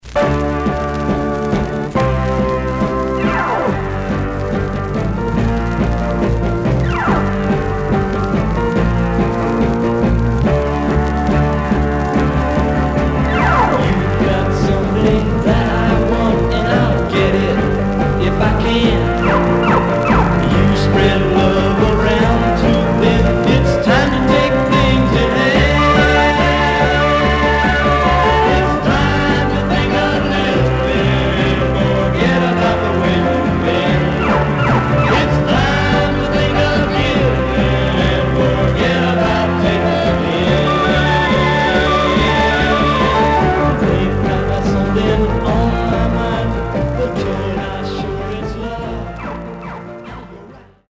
Surface noise/wear Stereo/mono Mono
Garage, 60's Punk Condition